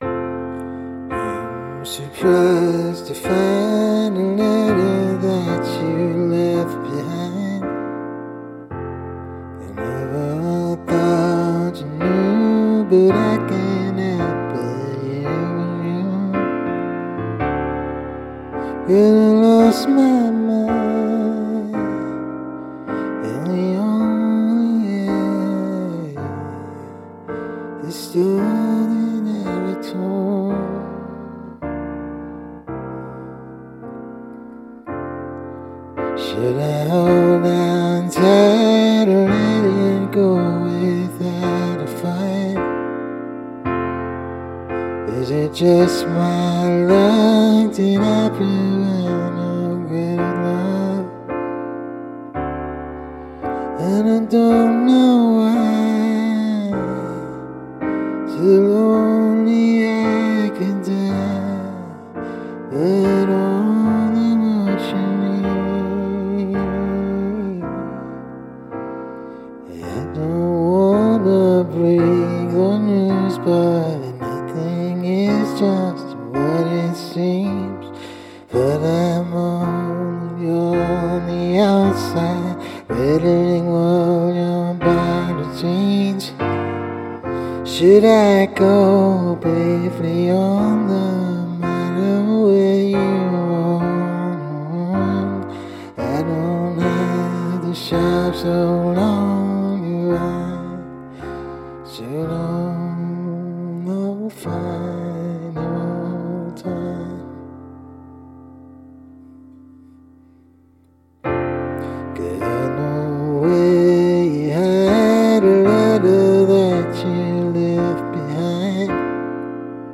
there’s a stevie wonder-ish lead/shred at the end (~2:22) that i was just messing around with but ended up liking lol